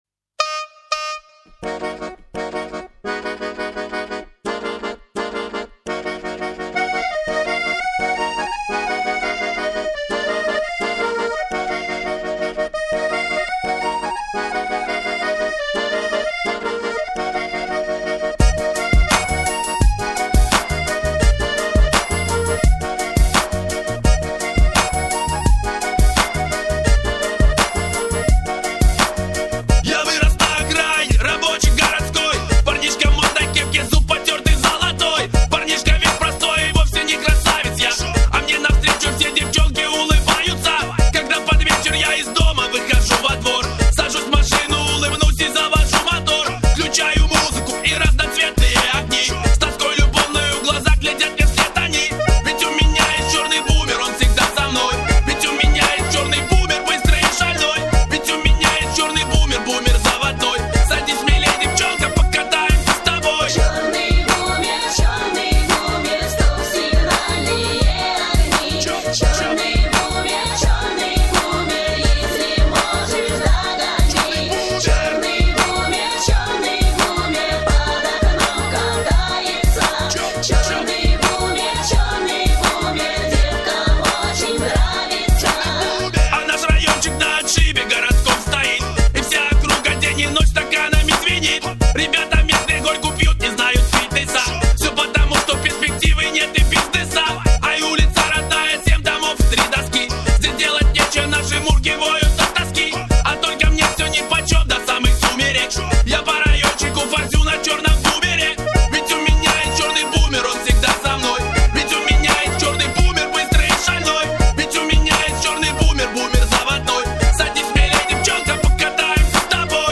他的音乐融合俄语民间音乐和动感新潮的现代音乐。